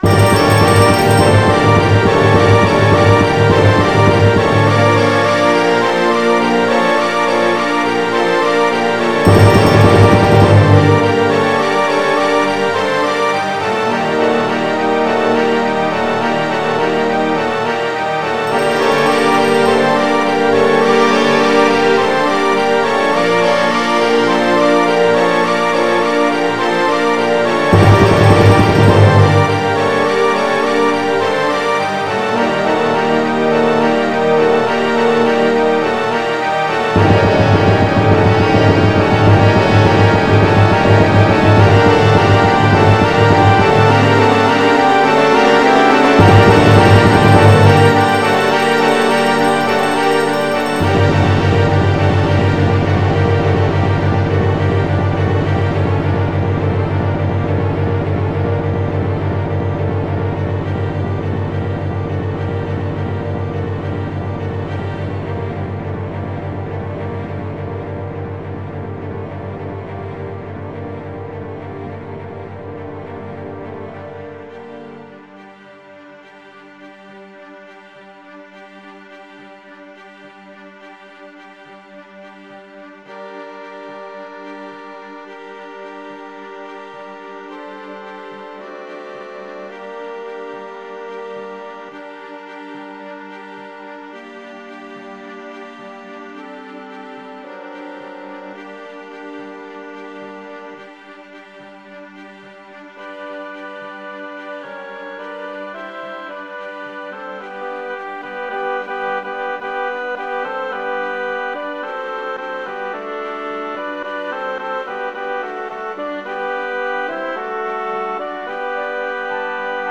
MIDI Music File
Title Anfang ohne Sysx Type General MIDI